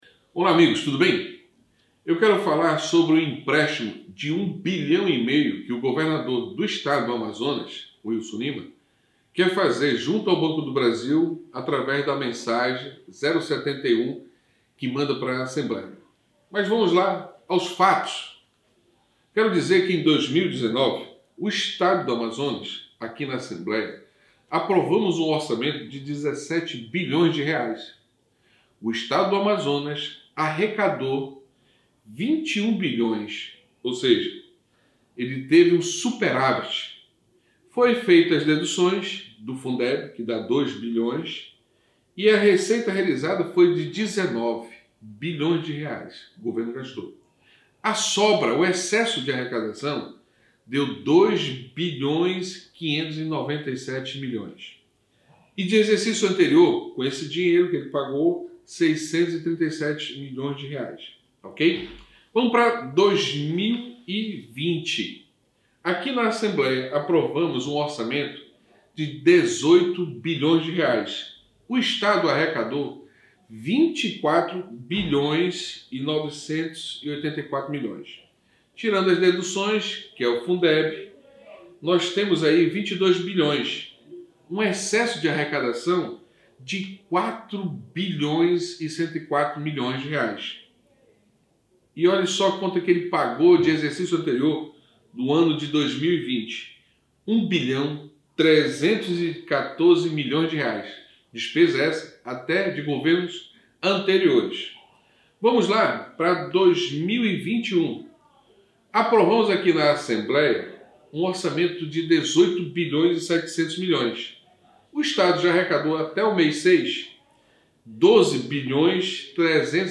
Nesta quinta-feira (1º/7), o deputado Dermilson Chagas (Podemos) usou a tribuna da Assembleia Legislativa do Amazonas (Aleam) para fazer duas denúncias contra o governador Wilson Lima, que enviou duas mensagens governamentais para a Casa, sendo uma para contrair empréstimo de R$ 1,5 bilhão junto ao Banco do Brasil, alegando de forma genérica que o recurso será utilizado para a recuperação da economia amazonense, sem dar mais explicações, e a segunda tem por finalidade utilizar o excesso de arrecadação bimestral das contribuições financeiras e os seus superávits financeiros anuais apurados, até o exercício de 2021, não utilizados, possam ser aplicados a cobertura do déficit previdenciário do Poder Executivo.